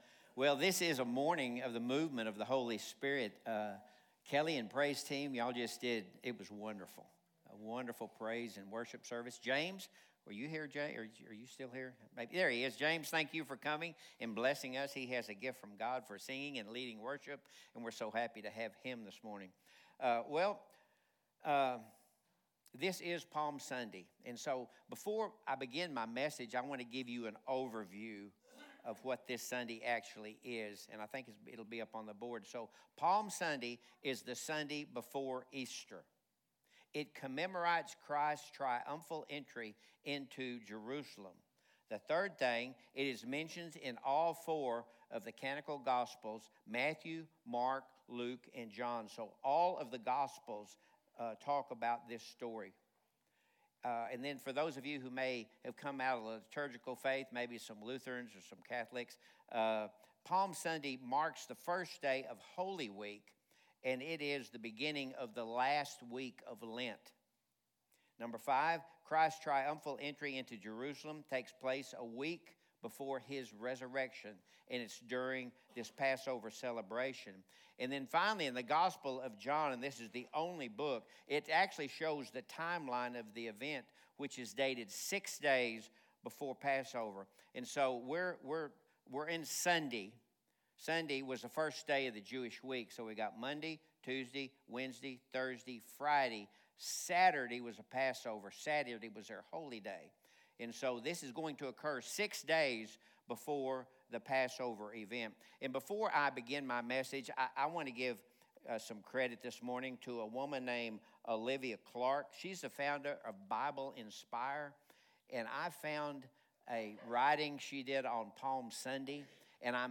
The Gathering at Adell Audio Sermons Who Is Jesus To You?